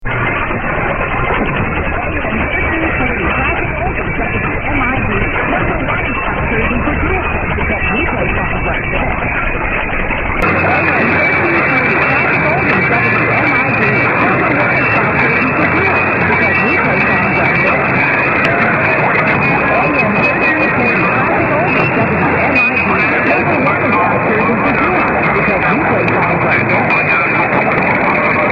After some attempts at recording at dawn, and hearing virtually nothing, despite the beverage antennas, signals are again starting to re-appear as if by magic.